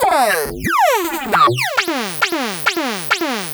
Session 04 - SFX 01.wav